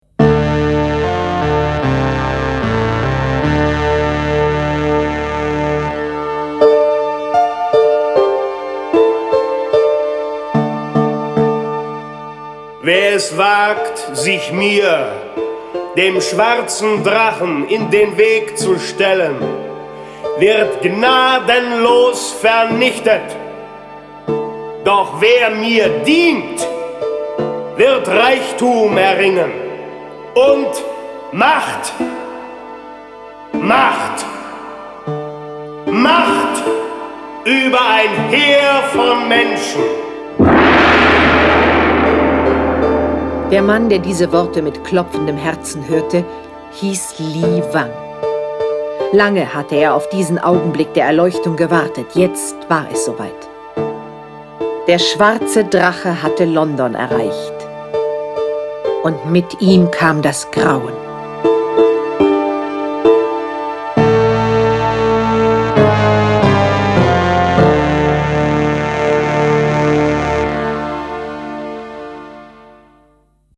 John Sinclair Tonstudio Braun - Folge 46 Die Nacht des Schwarzen Drachen. Jason Dark (Autor) diverse (Sprecher) Audio-CD 2016 | 1.